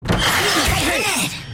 2024_April_Fools_Update_Dupe_Voice_Line_-1.mp3